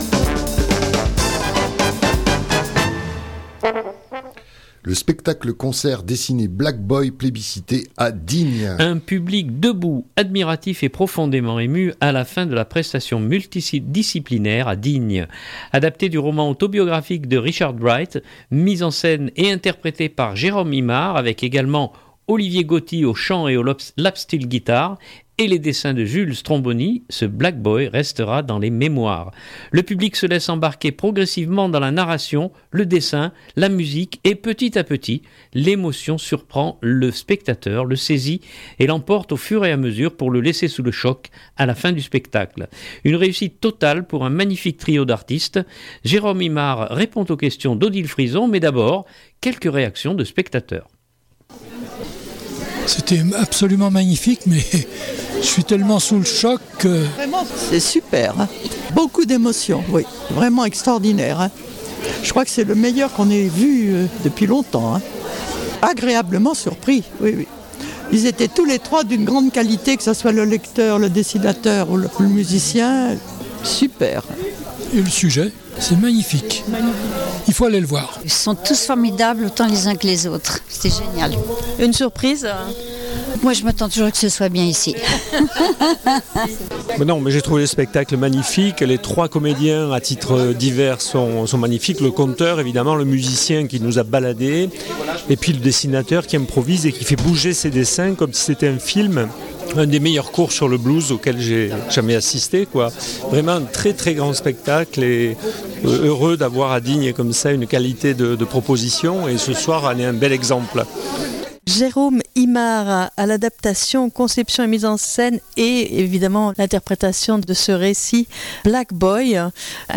Mais d’abord quelques réactions de spectateurs.